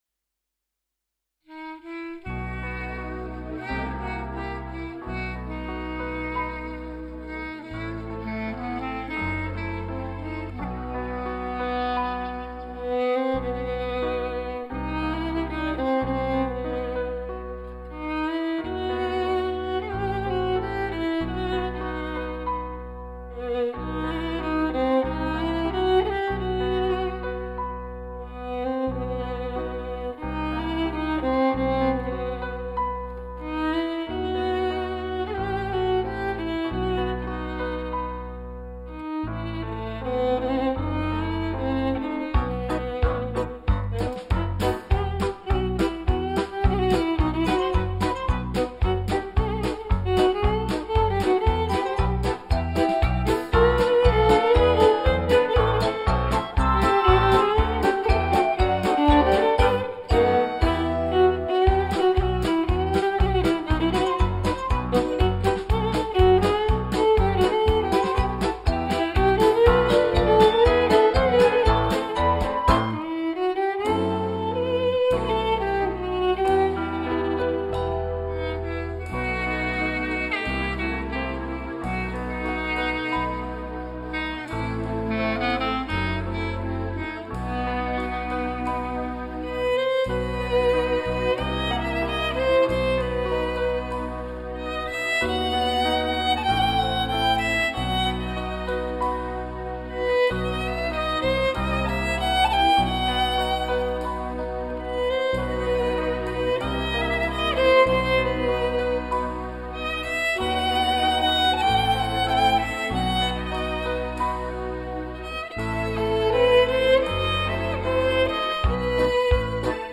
Предлагаю послушать несколько еврейских мелодий.
Violin